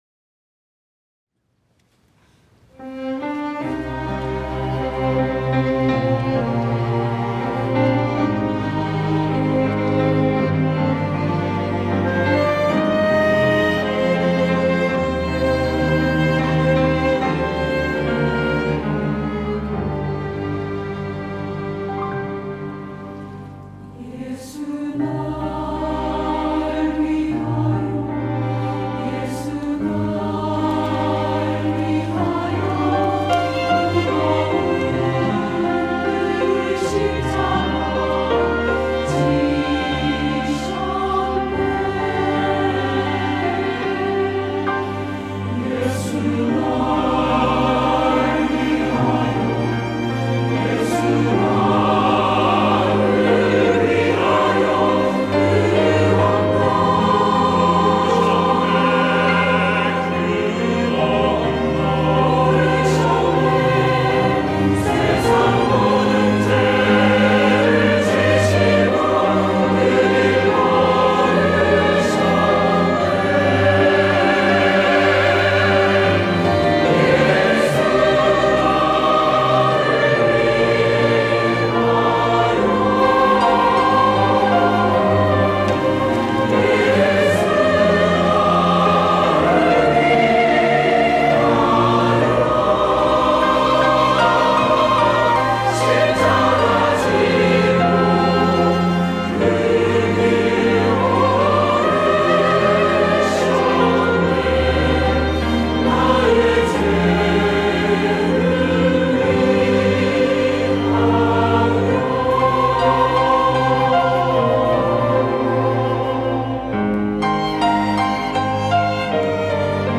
호산나(주일3부) - 예수 나를 위하여
찬양대